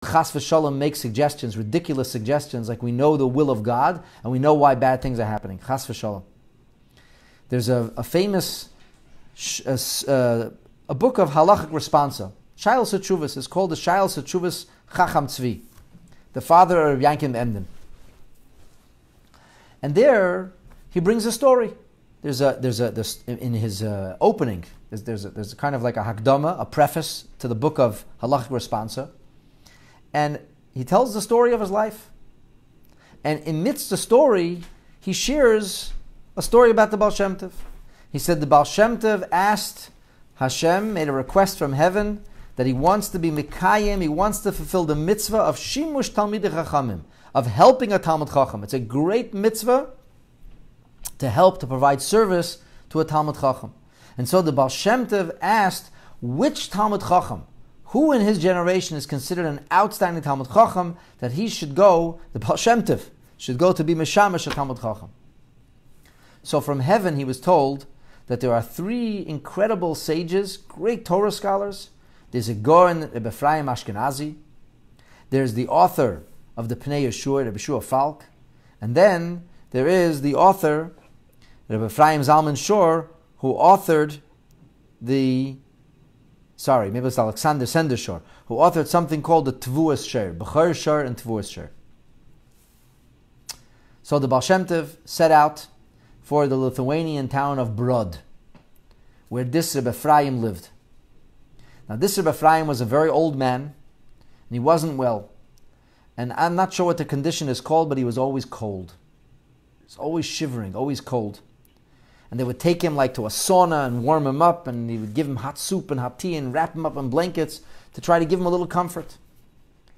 Post-Shavuot/Motzai Shabbat Farbrengen